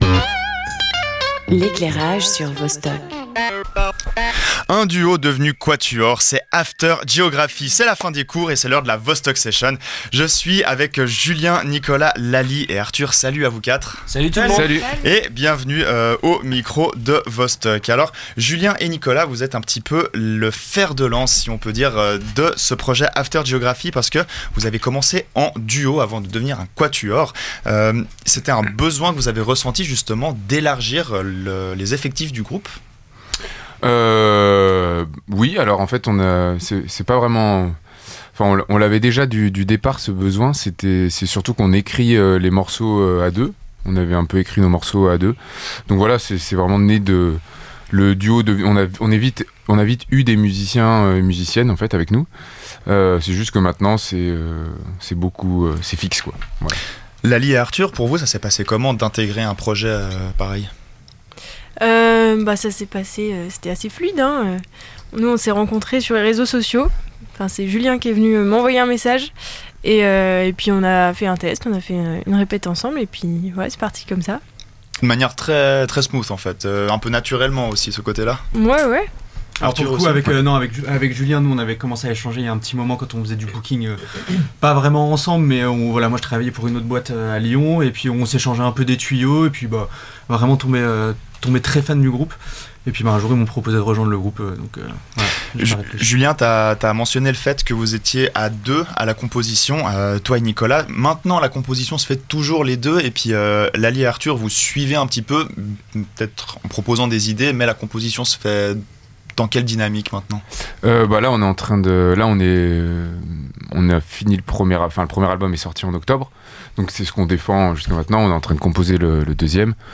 After Geography en interview